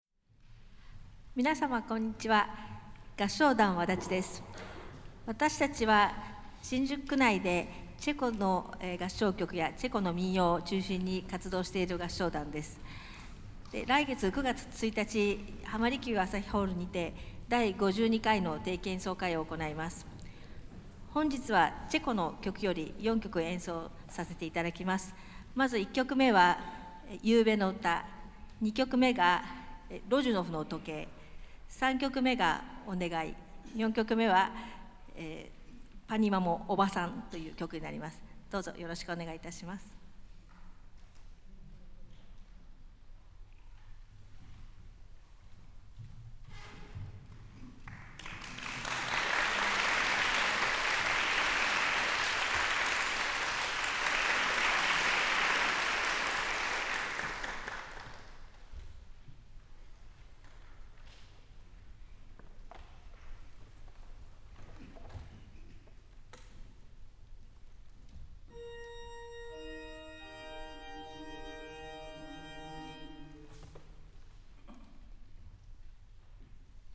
挨拶